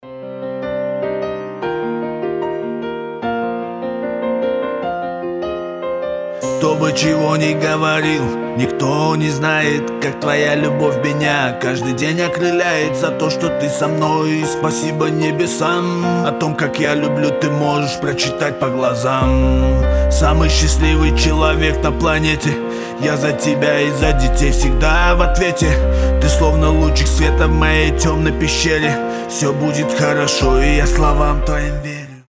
лирика
Хип-хоп
русский рэп
пианино
романтичные